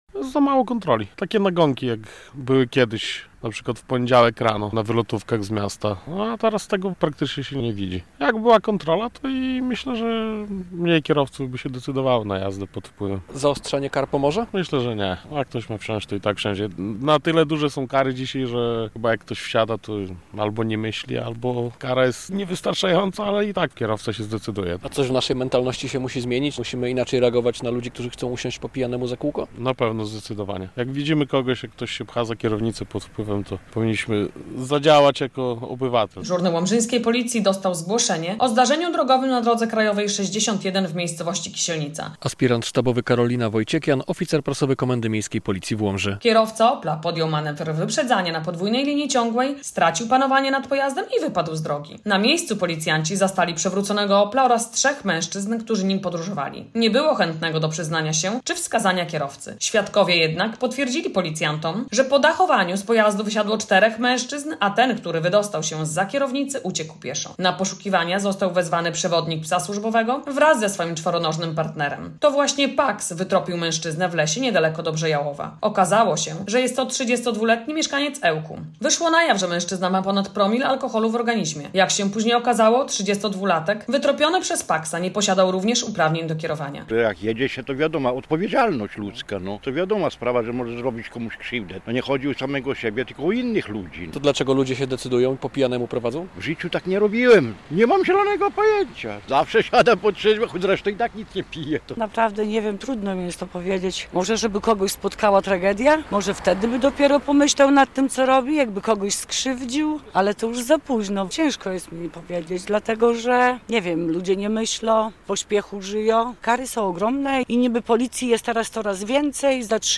pytał mieszkańców Łomży, dlaczego tak wielu kierowców decyduje się wsiąść za kierownicę "na podwójnym gazie"